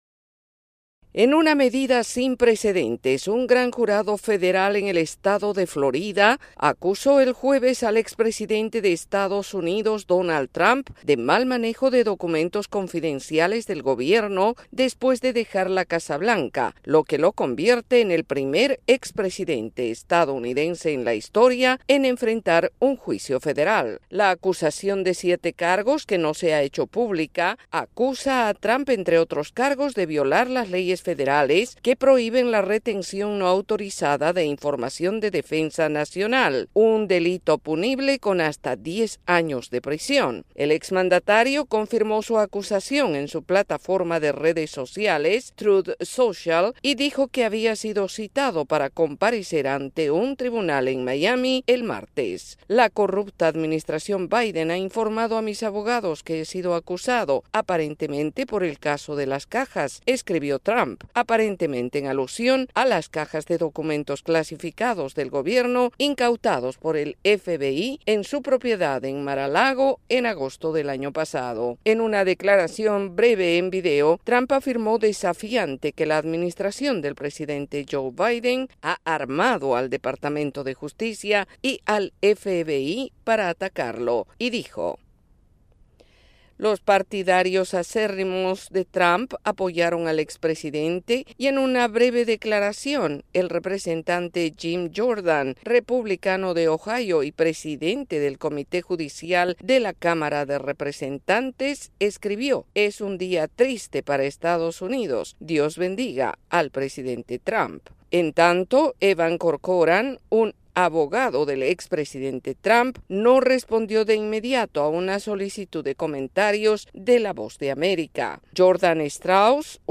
El expresidente Donald Trump es encausado por el mal manejo de documentos clasificados y deberá comparecer en corte en Florida el martes. El informe